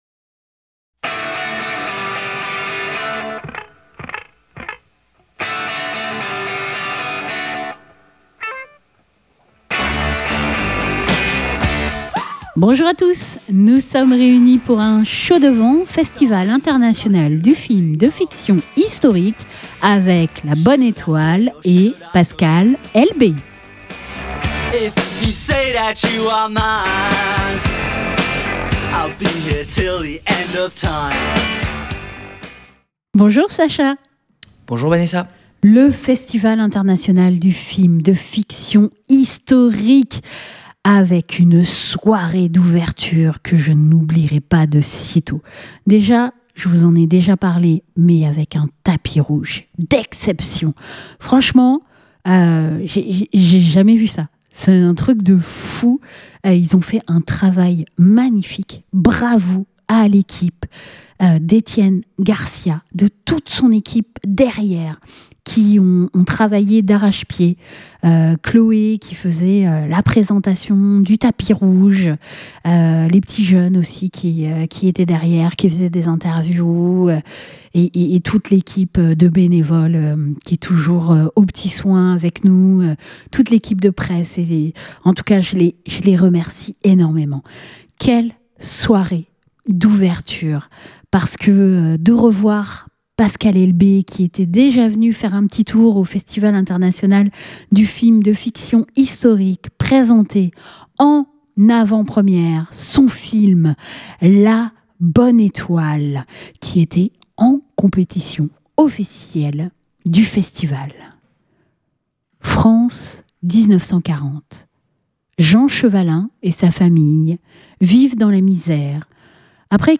16 novembre 2025 Écouter le podcast Télécharger le podcast C'est au cours de la 11ème édition du festival international du film de fiction historique que nous avons pu découvrir en ouverture le petit bijou "La Bonne étoile" réalisé de main de maître par Pascal Elbé et traitant avec légèreté tendresse et émotions de la seconde guerre mondiale. Nous avons eu le privilège lors du festival d'avoir une entrevue avec le réalisateur, scénariste et acteur Pascal Elbé.